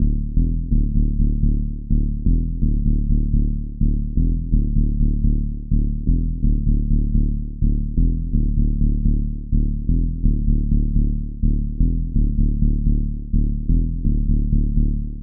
• Punchy Tech House Bass Rhythm - EDM - Dm - 126.wav
Punchy_Tech_House_Bass_Rhythm_-_EDM_-_Dm_-_126_BkN.wav